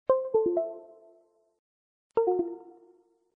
Звуки подключения, отключения USB
На этой странице собрана коллекция звуков, которые издает компьютер при подключении и отключении USB-устройств.